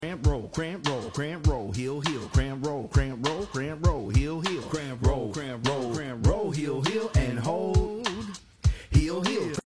Tap Level 7